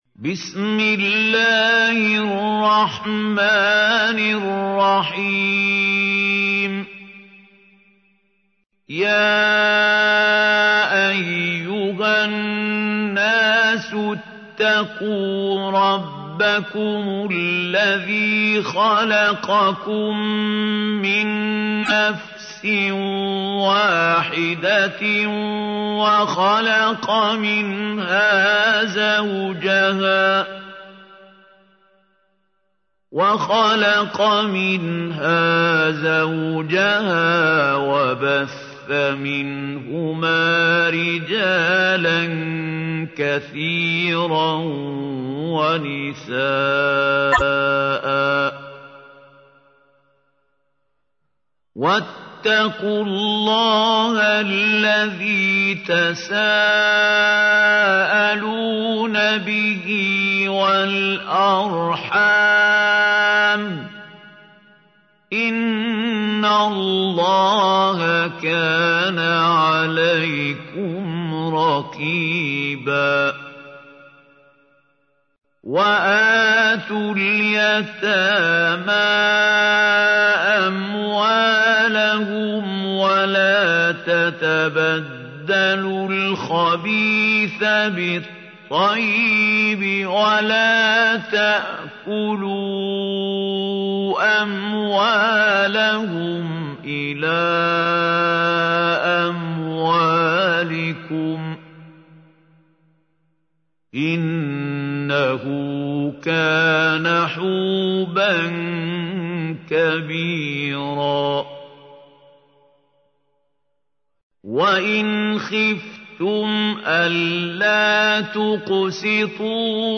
تحميل : 4. سورة النساء / القارئ محمود خليل الحصري / القرآن الكريم / موقع يا حسين